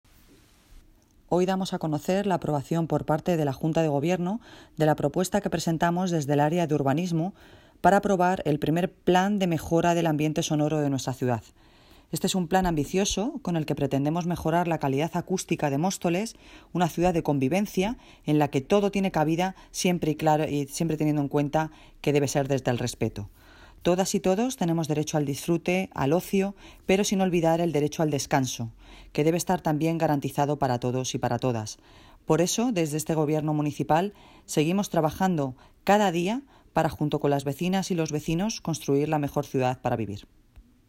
Audio - Noelia Posse (Alcaldesa de Móstoles) Sobre Plan de Mejora del Ambiente Sonoro
Audio - Noelia Posse (Alcaldesa de Móstoles) Sobre Plan de Mejora del Ambiente Sonoro.mp3